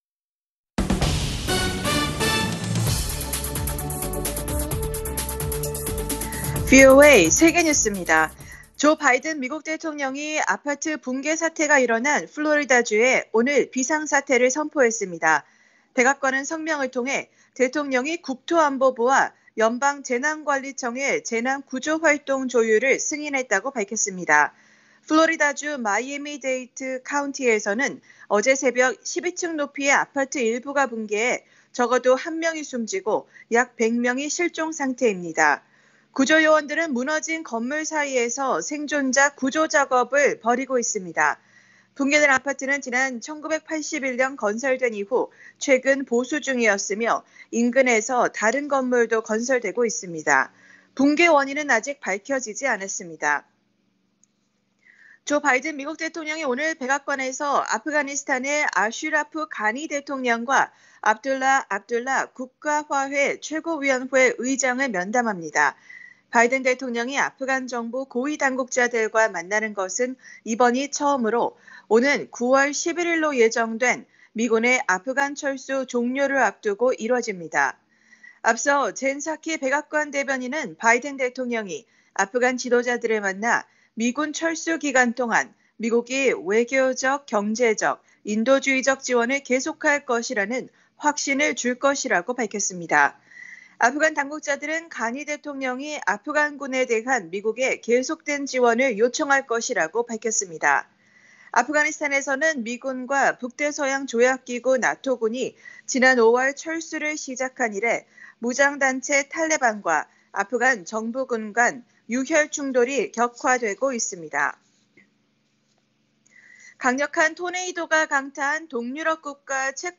VOA 한국어 간판 뉴스 프로그램 '뉴스 투데이', 2021년 6월 25일 3부 방송입니다. 미국 국방부는 한국전쟁 71주년을 맞아 한국은 미국의 소중한 동맹이며 안보와 억제를 제공하기 위해 많은 미군이 주둔해 있다고 밝혔습니다. 아미 베라 미 하원 동아태소위 위원장은 조 바이든 행정부가 북한과의 대화에 열려 있다며 궁극적인 목표는 한반도에서 핵 위협을 제거하는 것이라고 말했습니다. 미국과 한국, 일본의 국방전문가들은 북한의 핵 고도화에 따른 역내 불안정이 증대하고 있다고 밝혔습니다.